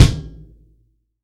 TUBEKICKO2-S.WAV